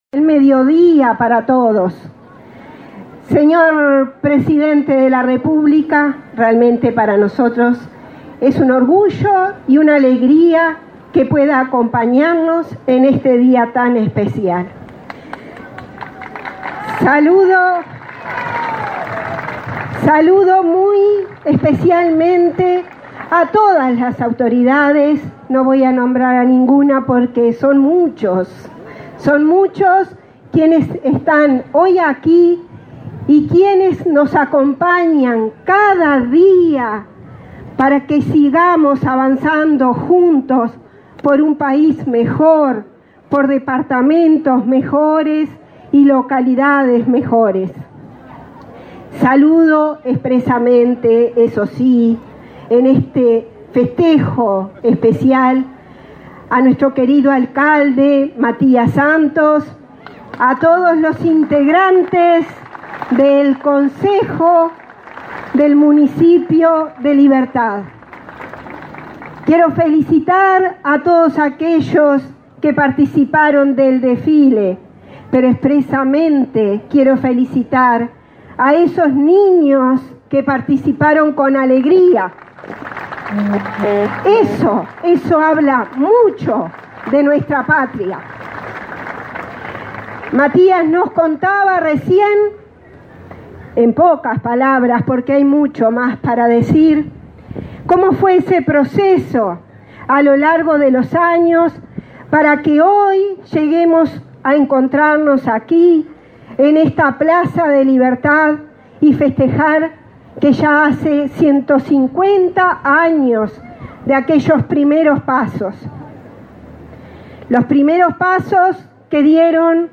Palabras de la intendenta de San José, Ana Bentaberri
El presidente de la República, Luis Lacalle Pou, participó, este 11 de diciembre, en la celebración del 150.° aniversario de la fundación de la ciudad
En la oportunidad, se expresaron la intendenta de San José, Ana Bentaberri, y otras autoridades.